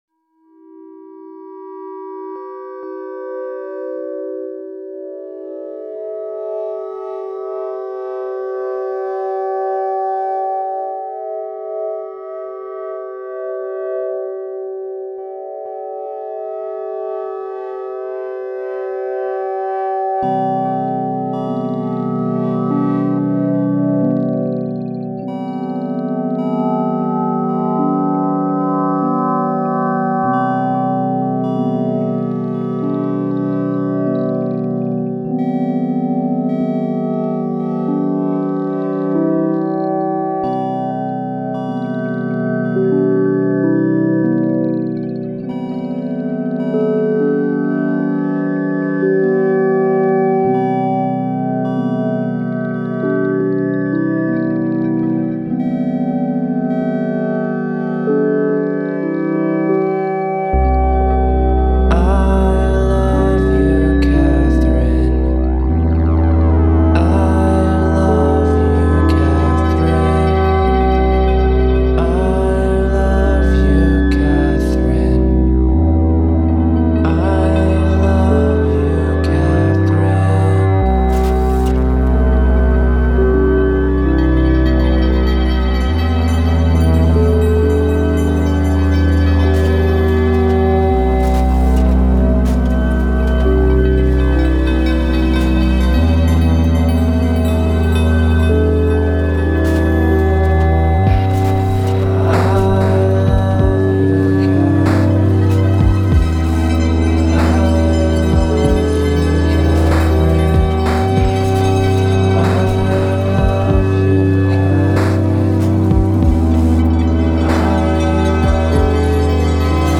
is gentle and sad song about a girl.